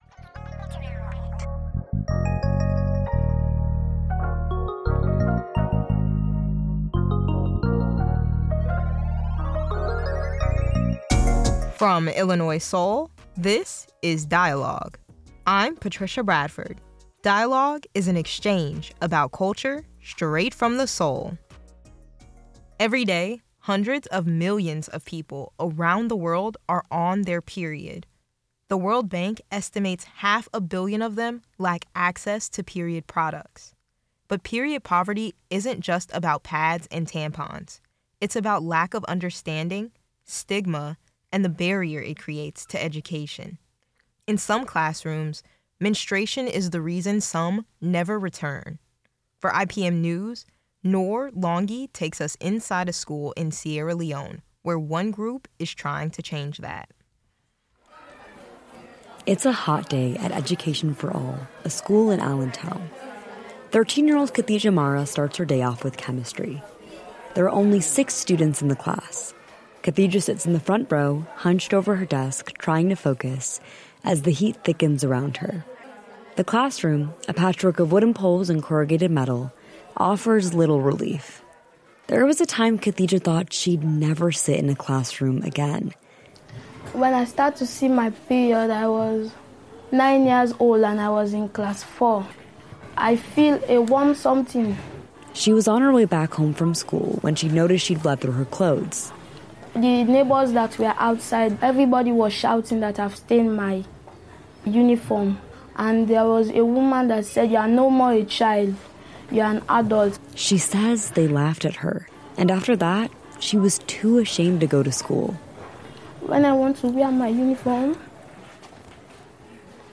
This episode of "Dialogue" explores how Black communities confront systemic control. It features reporting on period poverty in Sierra Leone, reactions from Congolese residents in Champaign to Trump’s travel ban, and a reflection on the 10-year anniversary of "Between the World and Me" by Ta-Nehisi Coates.